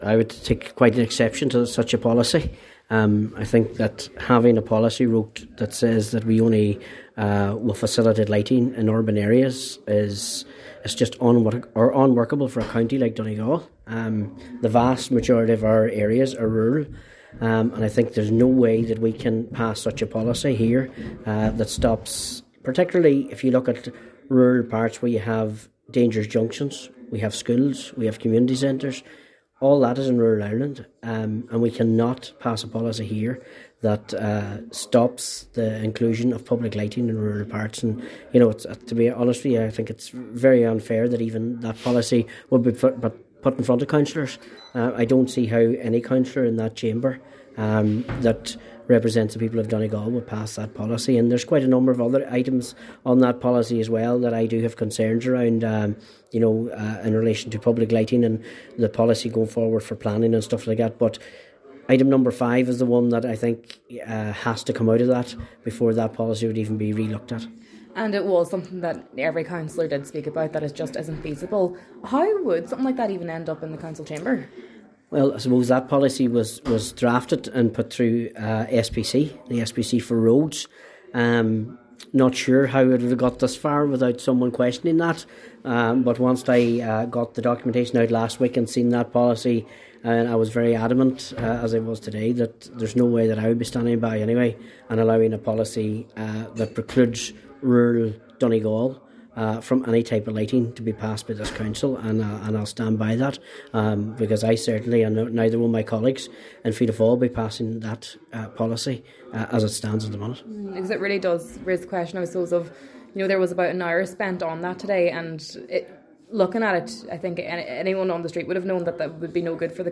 A proposed public lighting policy was opposed at today’s plenary meeting of Donegal County Council, as it would have restricted public lighting to urban speed-zone areas.